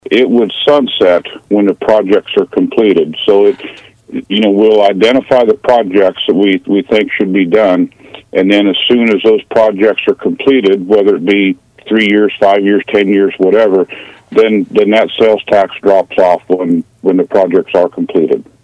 Commissioner Greg Riat on KMAN’s In Focus Tuesday.